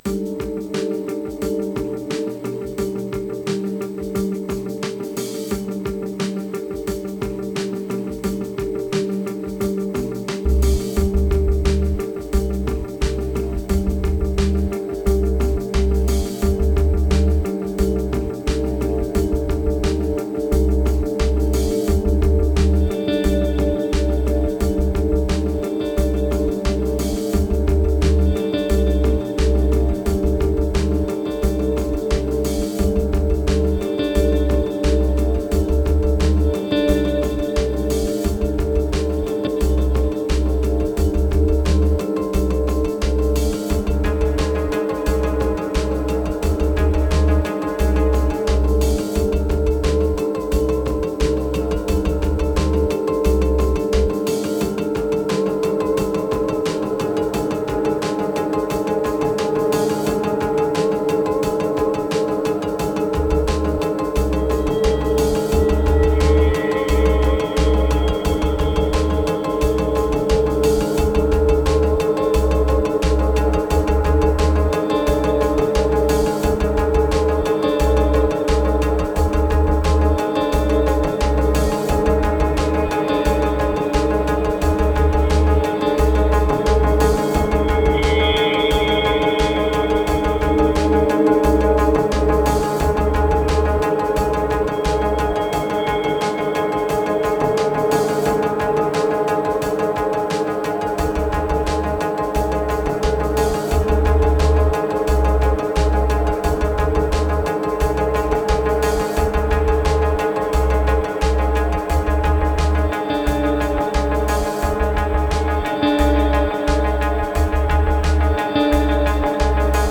1595📈 - 91%🤔 - 88BPM🔊 - 2023-02-19📅 - 597🌟